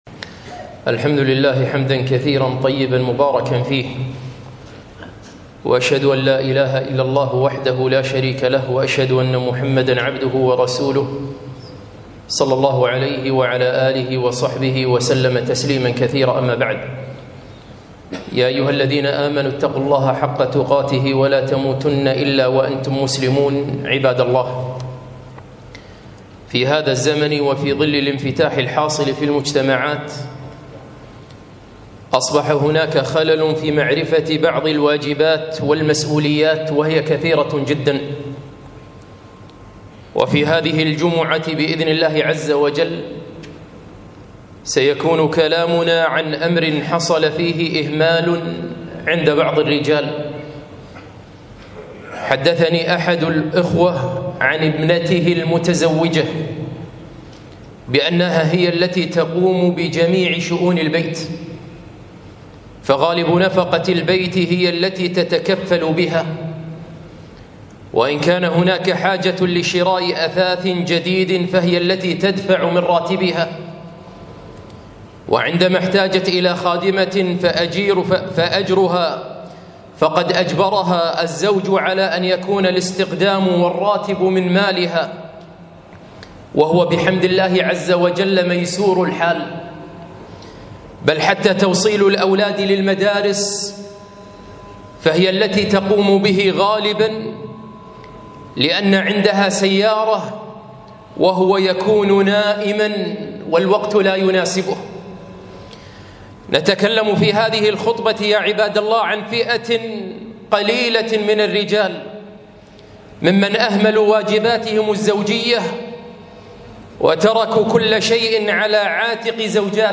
خطبة - إهمال بعض الأزواج